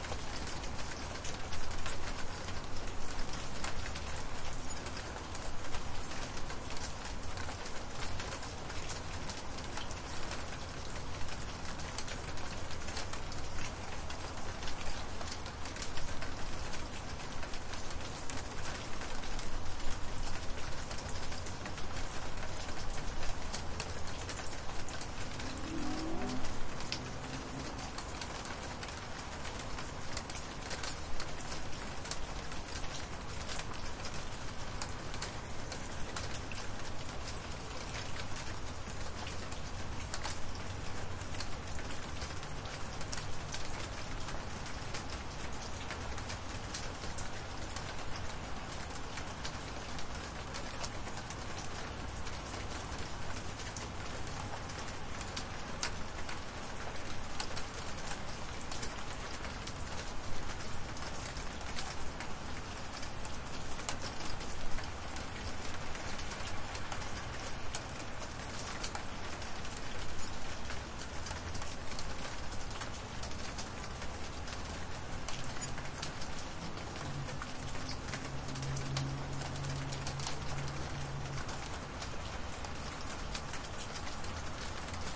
雨声 " 排水管
描述：小雨中我们家附近的一个排水管。用Zoom H1.
标签： 和平 春天 性质 现场录音
声道立体声